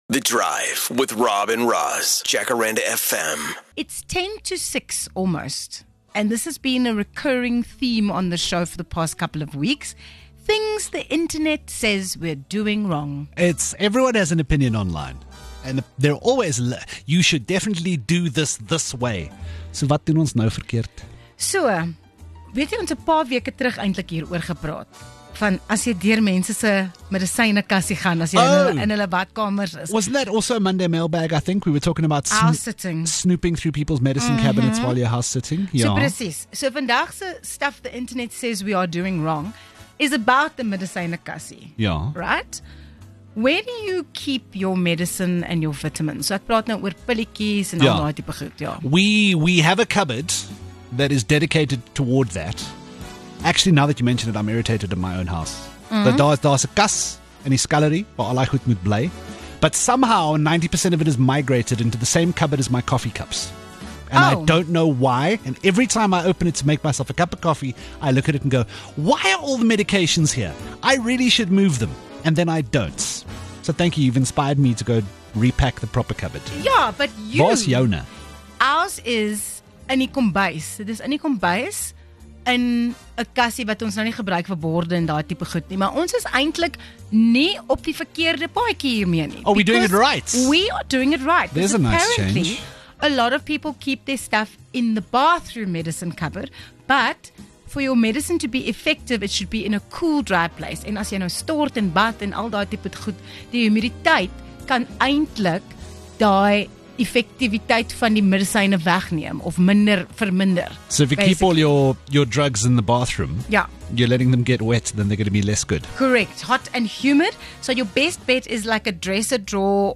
'More Music You Love' features heavily in this drive-time show, so you can expect a whole lot of music mixed in with popular topics like the weird things we all do, or breaking exclusive interviews that give us the story behind the story.